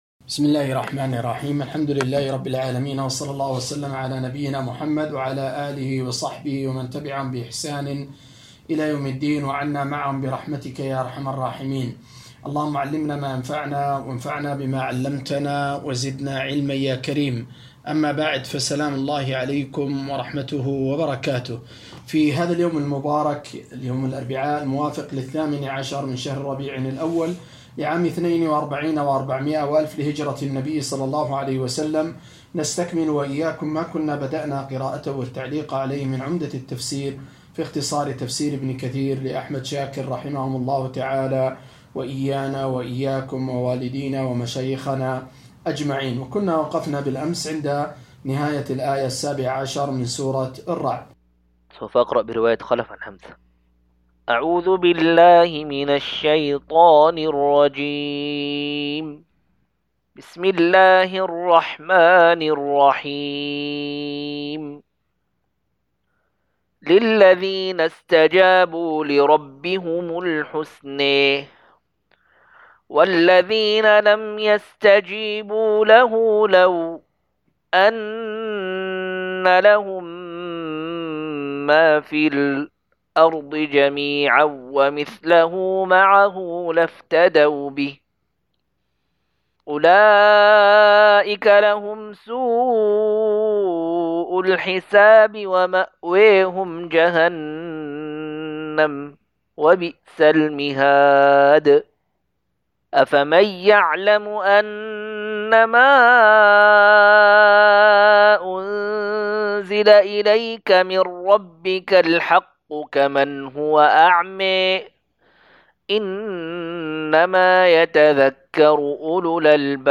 237- عمدة التفسير عن الحافظ ابن كثير رحمه الله للعلامة أحمد شاكر رحمه الله – قراءة وتعليق –